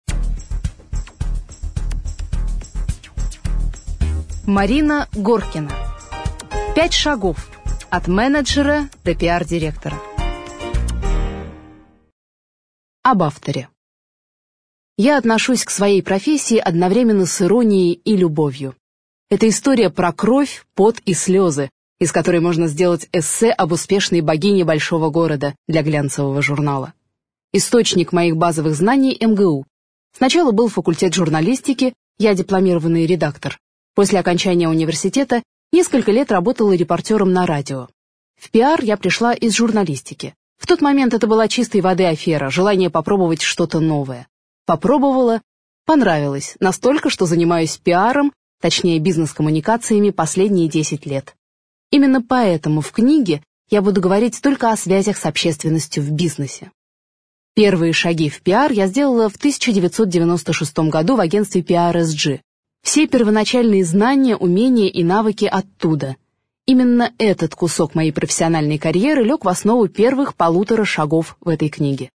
Аудиокнига Пять шагов от менеджера до PR-директора | Библиотека аудиокниг